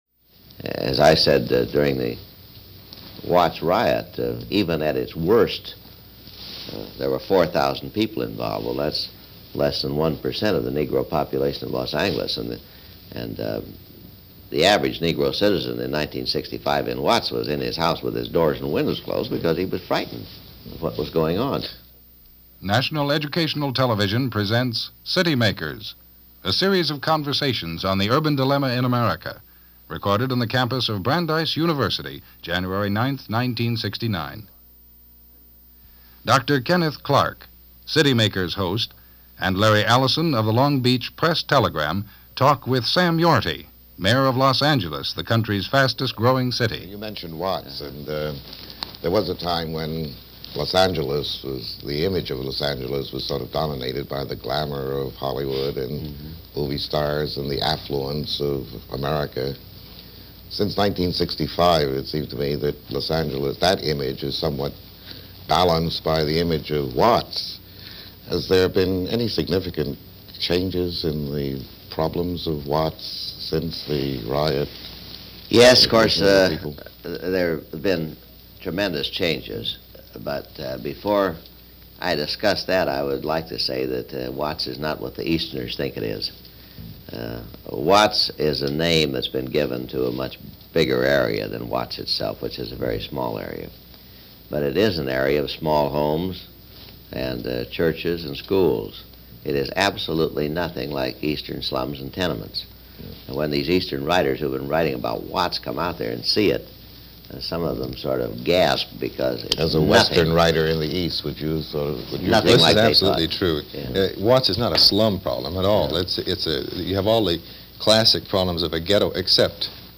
An Interview With L.A. Mayor Sam Yorty - 1969 - Past Daily Reference Room